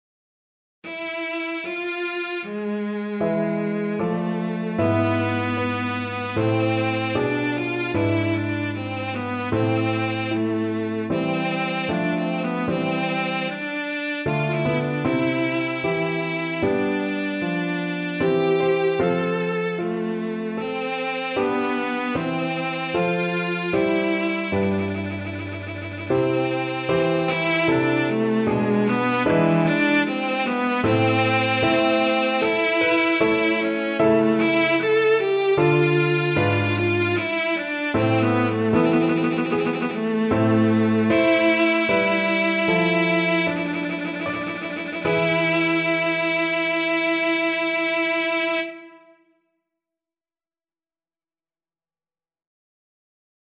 Classical Marcello, Benedetto Sonata No. 5 - Mvt 3 - Largo Cello version
Cello
A minor (Sounding Pitch) (View more A minor Music for Cello )
3/8 (View more 3/8 Music)
III: Largo =38
marcello_sonata_op2_no5_mvt3_VLC.mp3